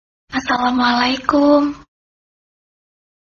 Suara Wanita Assalamualaikum
Kategori: Suara manusia
Keterangan: Suara Wanita "Assalamualaikum..." imut untuk nada dering ponsel dan notifikasi WA tersedia dalam format mp3.
suara-wanita-assalamualaikum-id-www_tiengdong_com.mp3